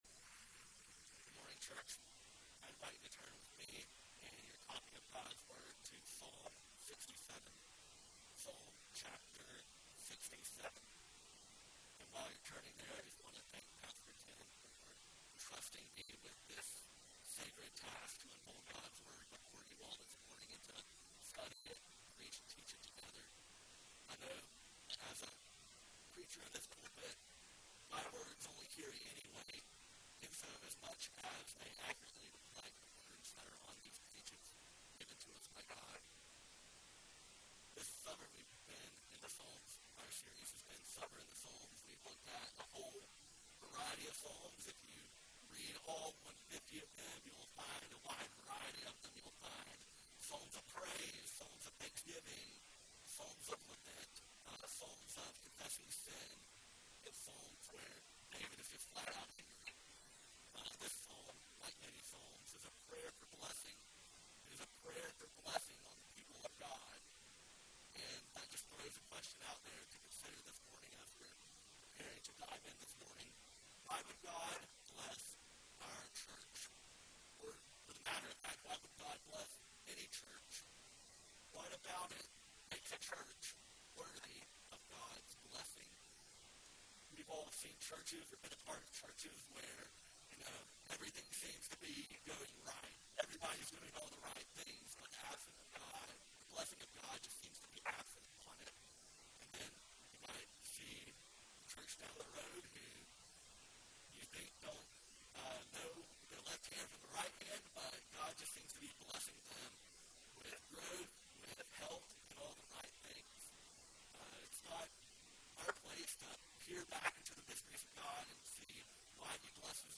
Sermon Series: Summer in Psalms